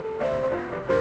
tfworld-hackathon / output / piano / 62-4.wav